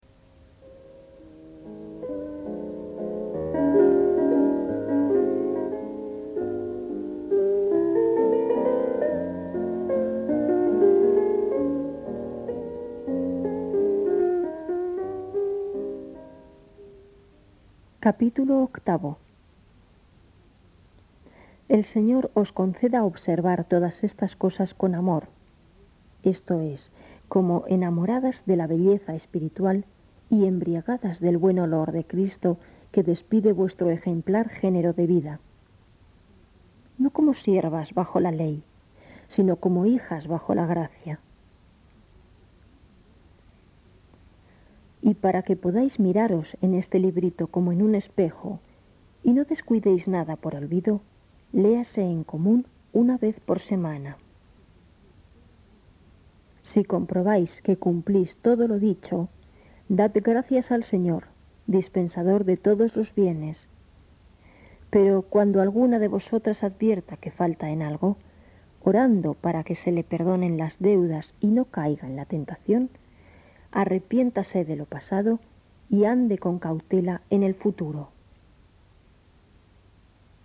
Regla de san Agustín en audio. Versión femenina. Voz de mujer. Español